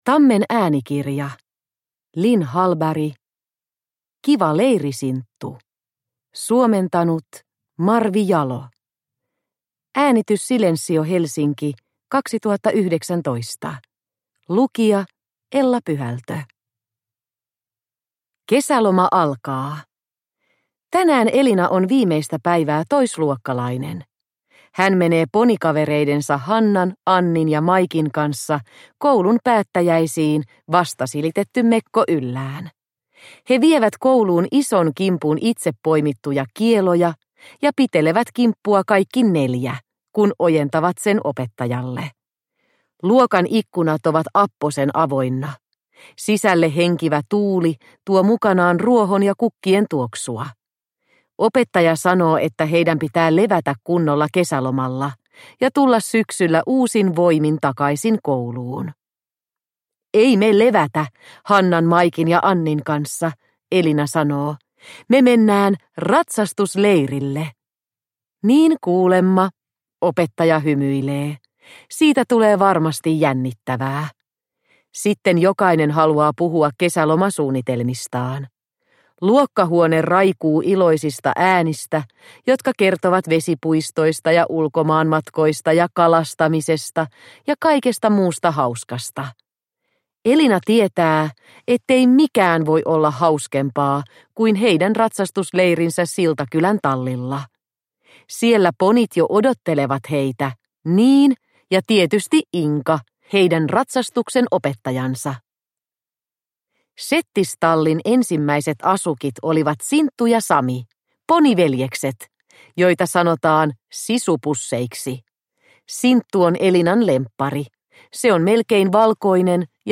Kiva leiri, Sinttu – Ljudbok – Laddas ner